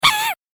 クリーチャーボイス9.mp3